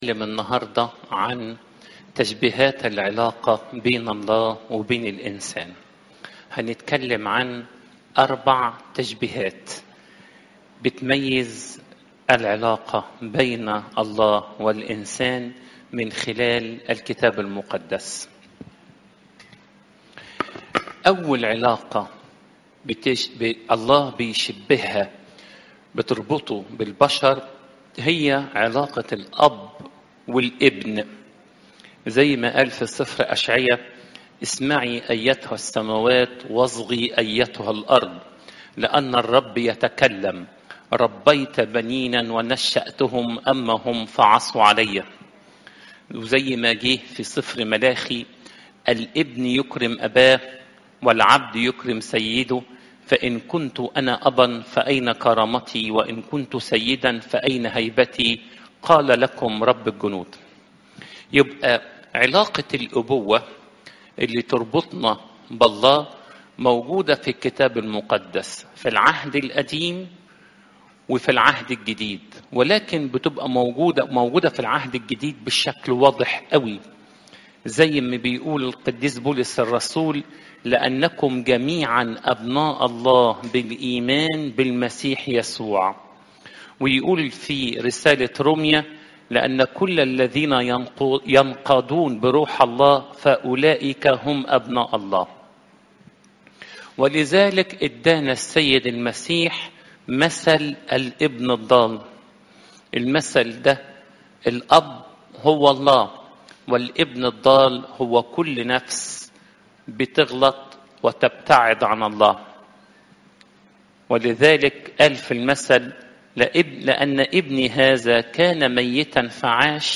إجتماع السيدات